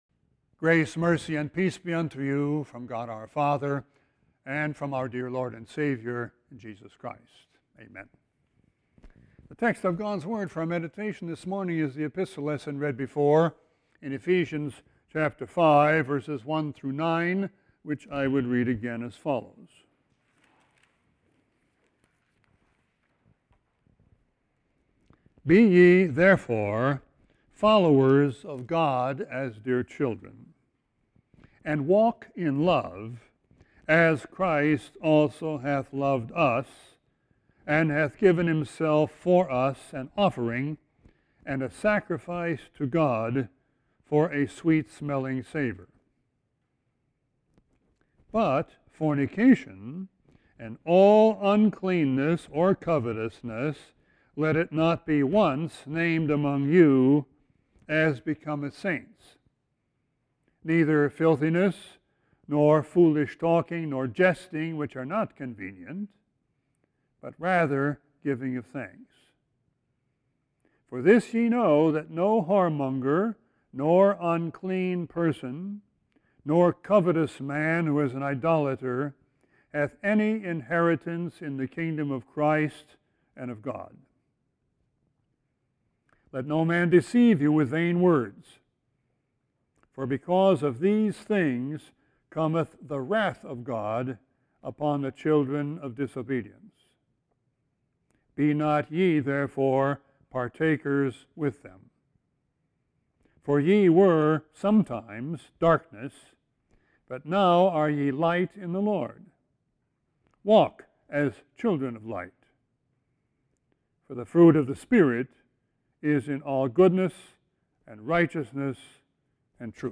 Sermon 3-15-20.mp3